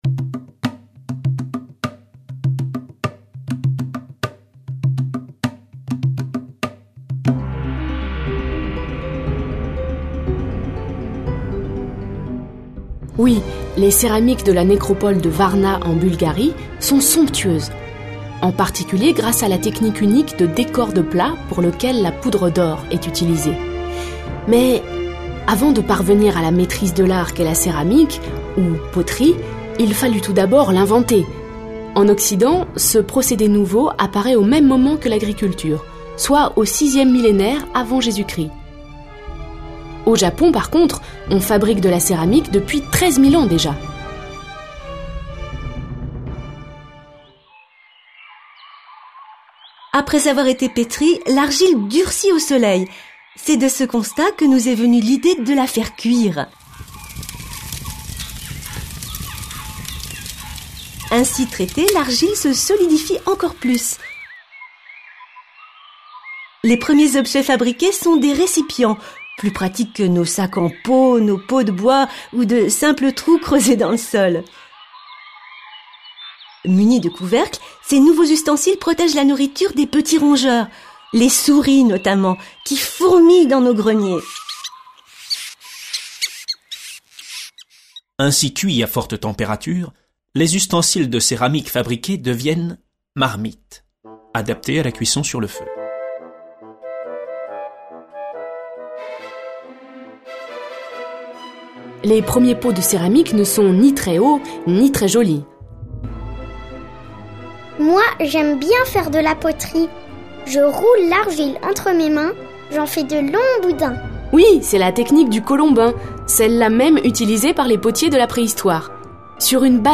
Ambiance sonore